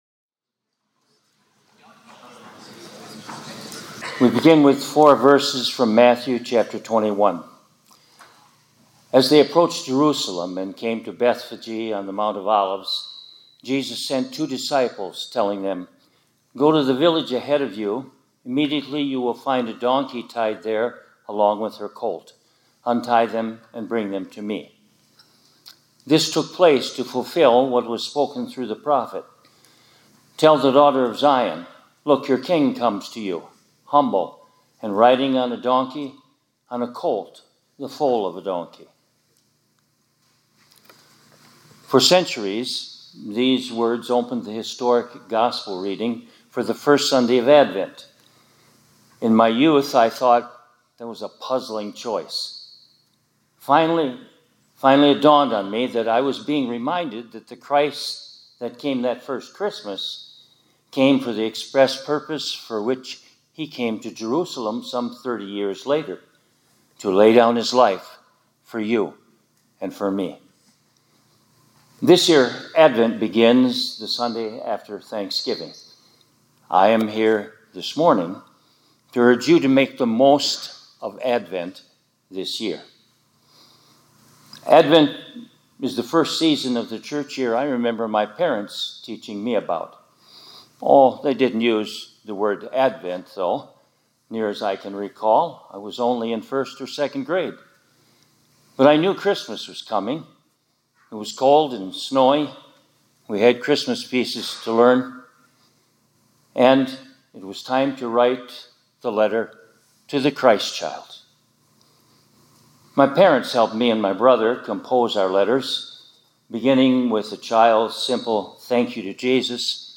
2024-11-25 ILC Chapel — Make the Most of Advent